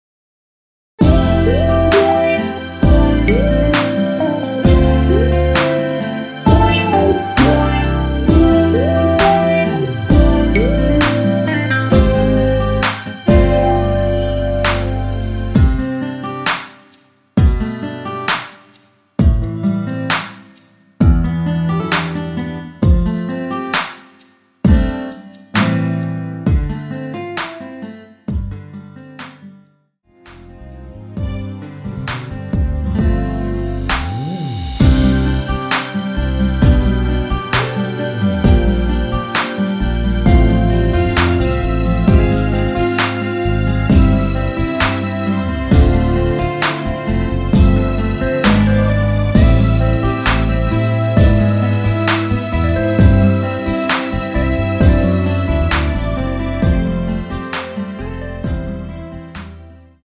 앞부분30초, 뒷부분30초씩 편집해서 올려 드리고 있습니다.
위처럼 미리듣기를 만들어서 그렇습니다.